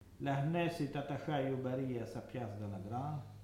Saint-Hilaire-de-Riez
Catégorie Locution